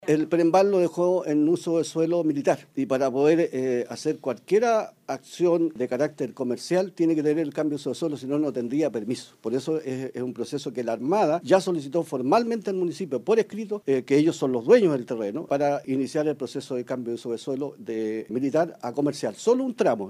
El alcalde de Concón, Óscar Sumonte, manifestó que la necesidad del aeropuerto ya no es solo una petición de la comuna si no más bien de la región.
cu-torquemada-2022-alcalde.mp3